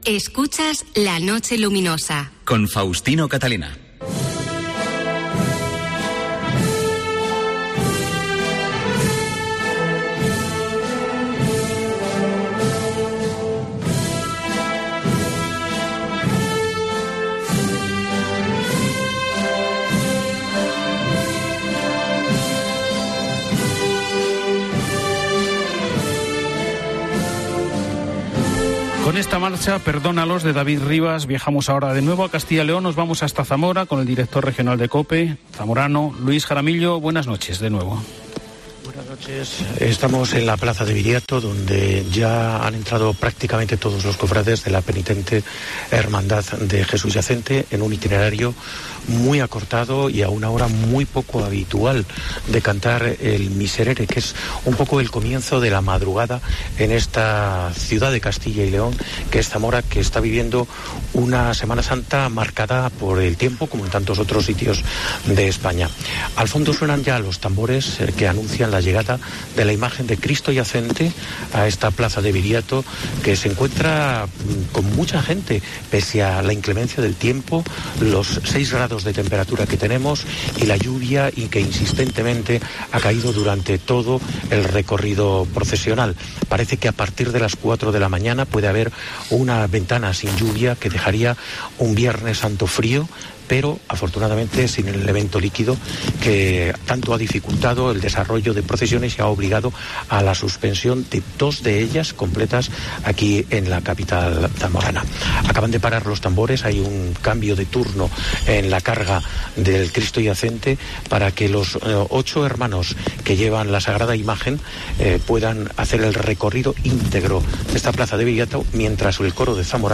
Retransmisión del Canto del Miserere (29/03/2024)
Semana Santa en Zamora